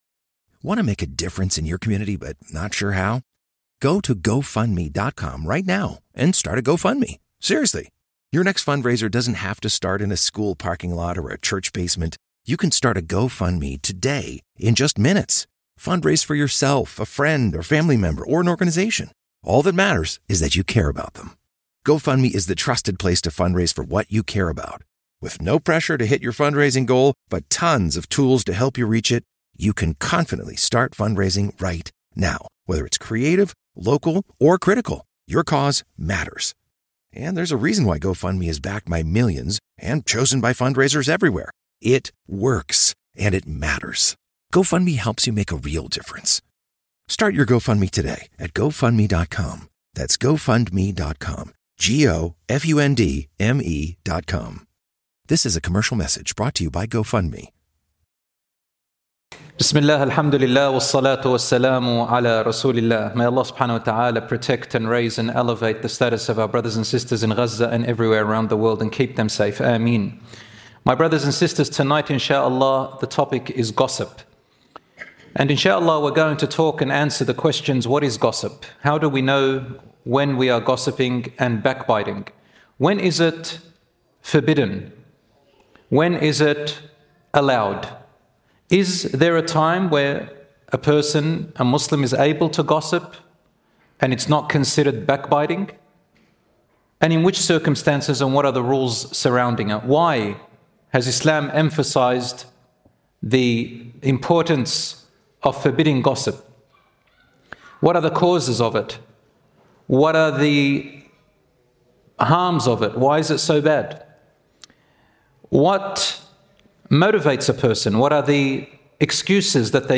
In this lecture, we explain the dangers of gossip and backbiting, their consequences in the Hereafter, when it’s permissible to speak about others, and how to purify our hearts and tongues from this sin.